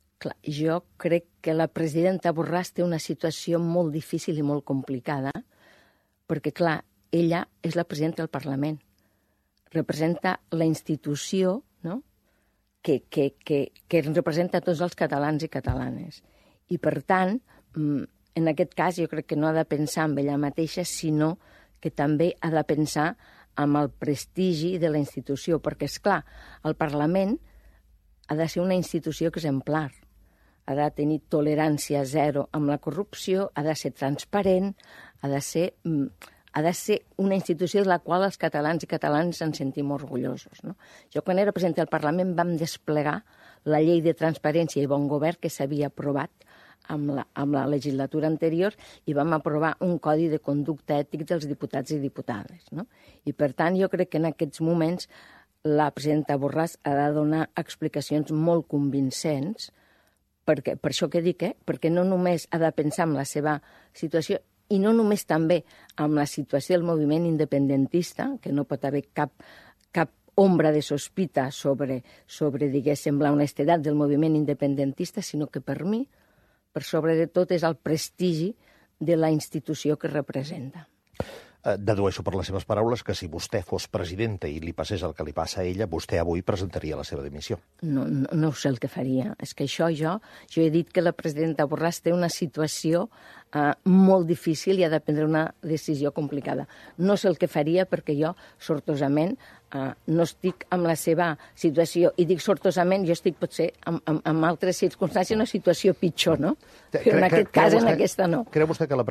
En una entrevista a la SER-Catalunya, no ha volgut respondre si creu que ha de dimitir, però ha deixat caure que “és presidenta i representa a tota la institució”.